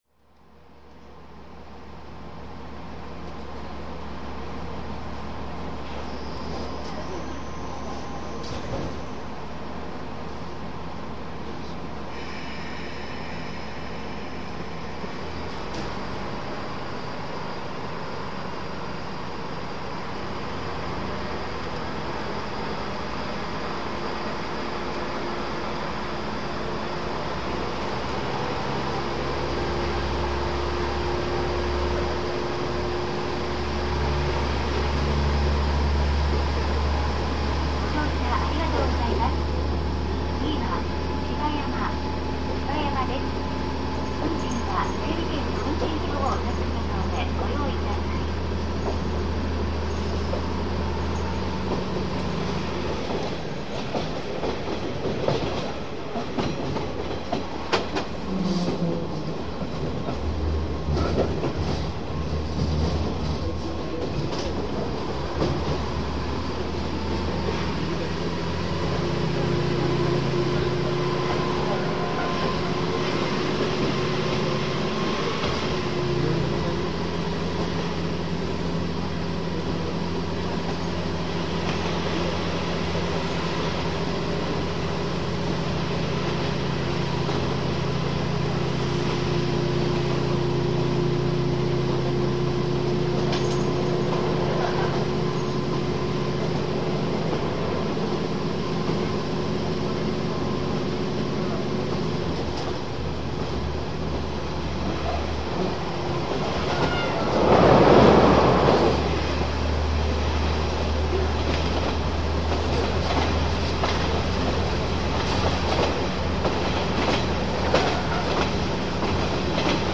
このページは全部「21世紀になってから録音した鉄道サウンド」です。
ファイルは全部MP-3でステレオです。